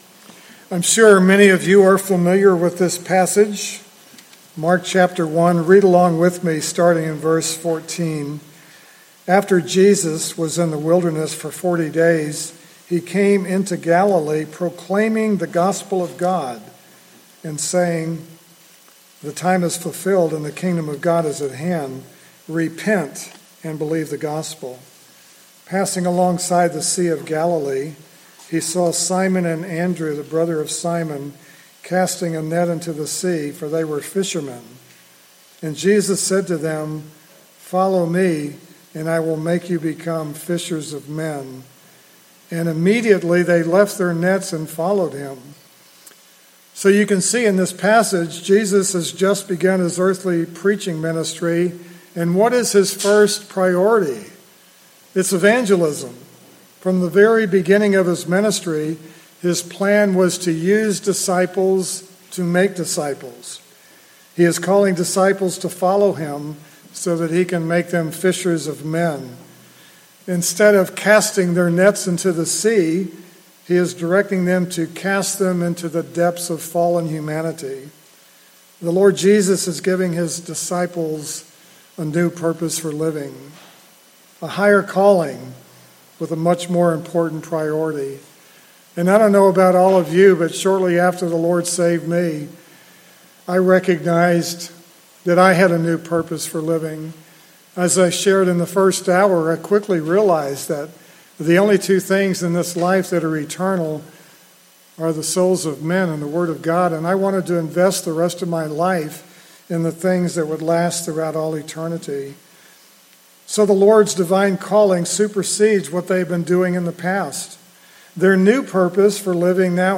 Mark 1:14-18 Service Type: Special Speaker Topics: Catholicism « The Purity of the Gospel While We Wait